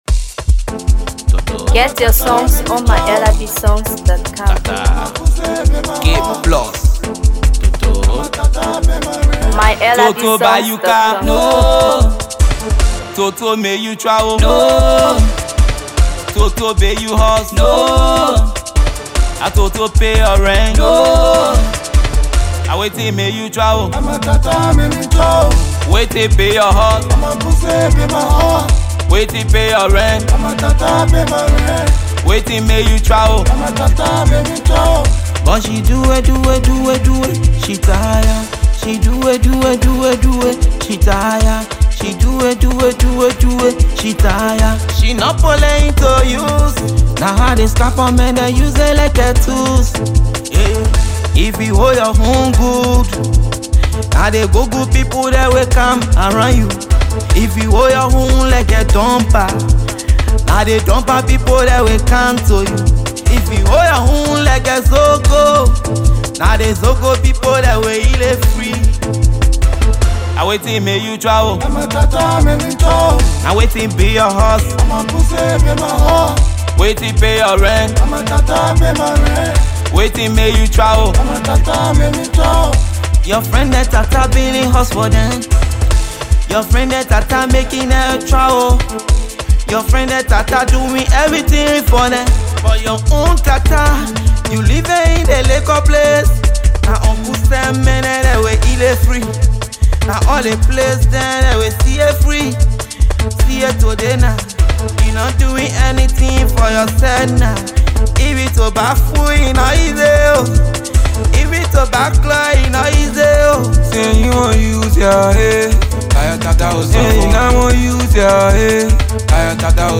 Afro Pop
Another hot street banger title